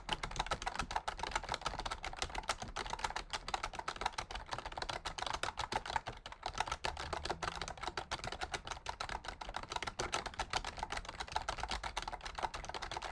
TypingFast
business clicking computer desktop fast hacking key keyboard sound effect free sound royalty free Sound Effects